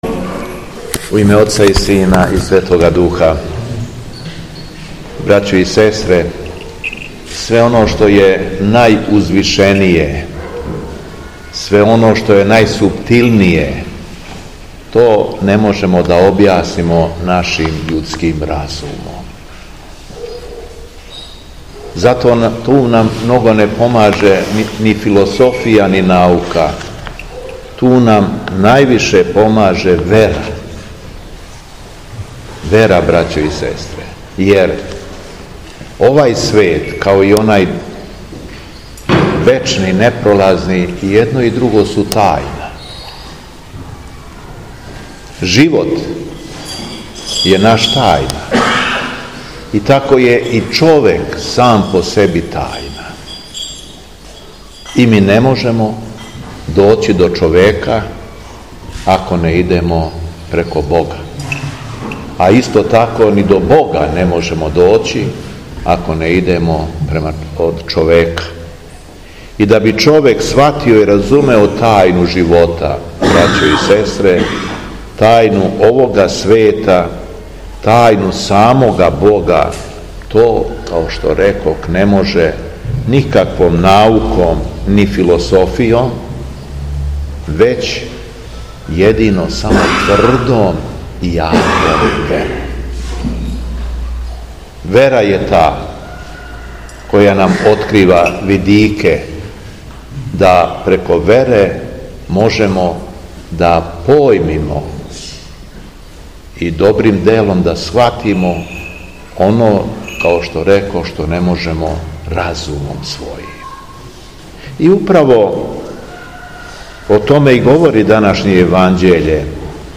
СВЕТА АРХИЈЕРЕЈСКА ЛИТУРГИЈА У РОГАЧИ - Епархија Шумадијска
Беседа Његовог Преосвештенства Епископа шумадијског г. Јована